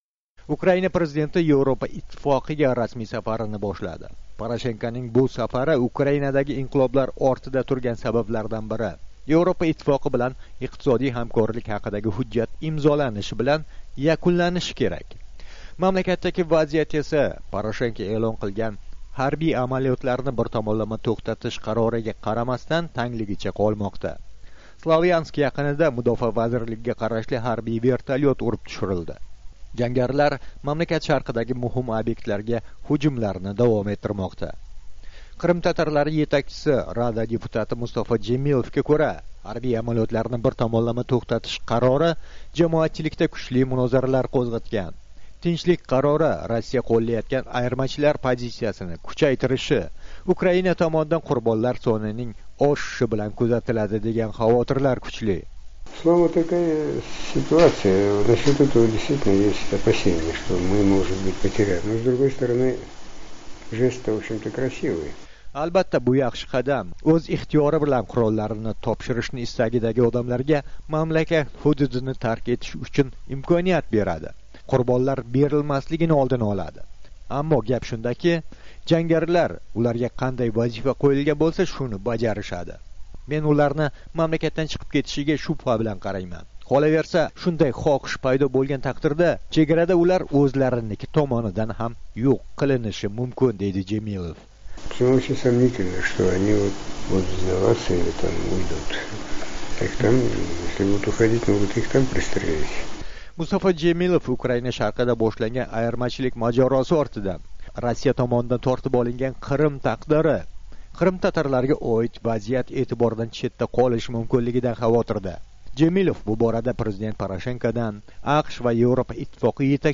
Mustafo Jemilev bilan suhbat - Kiyev, Ukraina